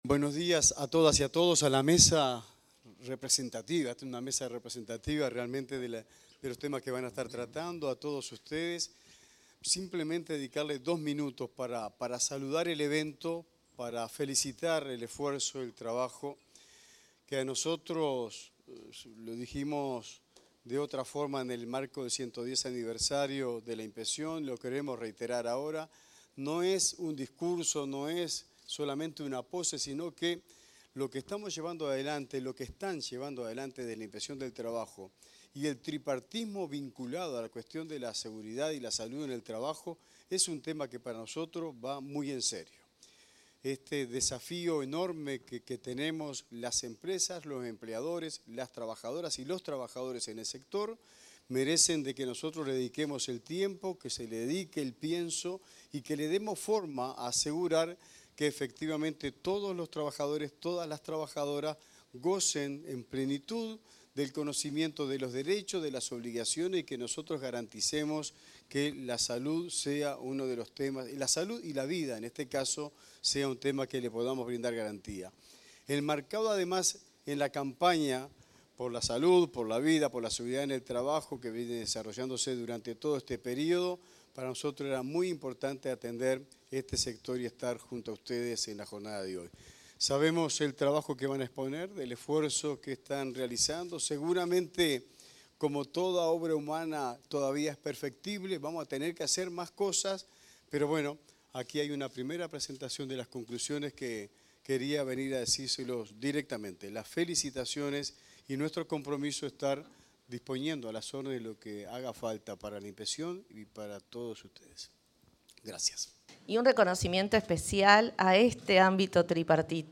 Palabras del inspector y subinspectora general del Trabajo, Luis Puig y Andrea Bouret
El inspector y la subinspectora general del Trabajo y la Seguridad Social, Luis Puig y Andrea Bouret, se expresaron en el relanzamiento de la Mesa